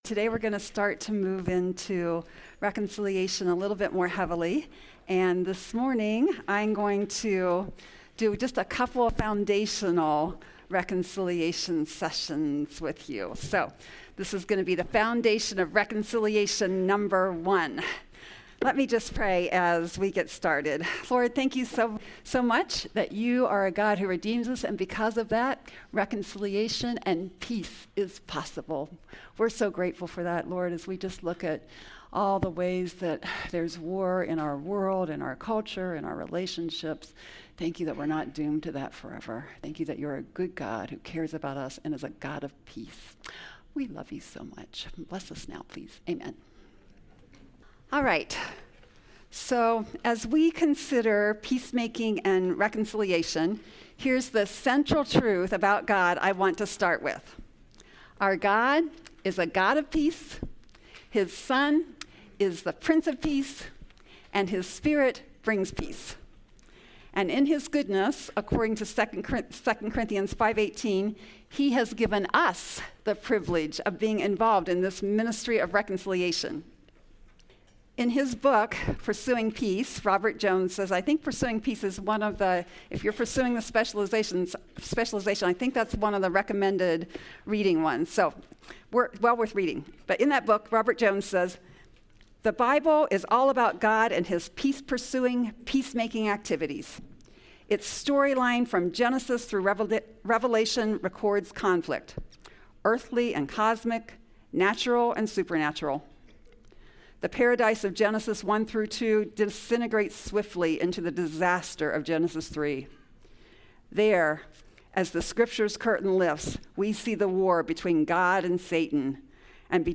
This is a session from the Biblical Counseling Training Conference hosted by Faith Church in Lafayette, Indiana. This session addresses the struggles that children who have been sexually or physically abused carry into adulthood.